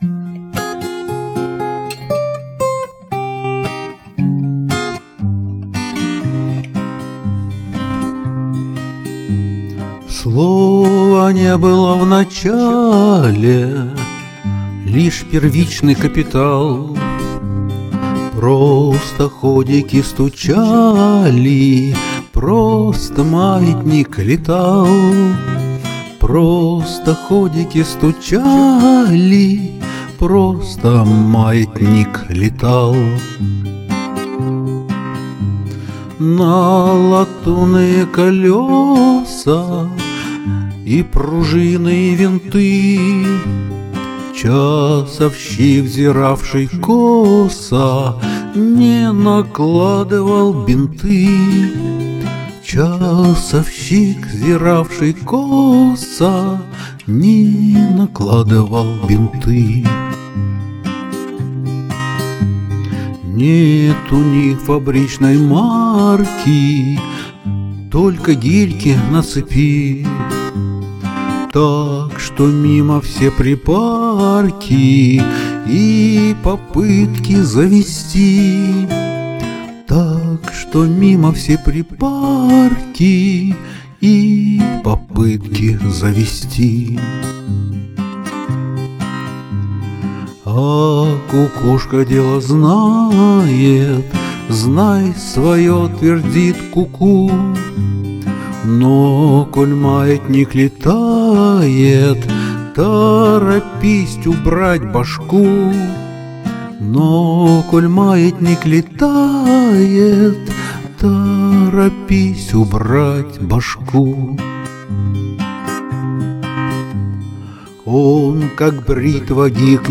• Жанр: Авторская песня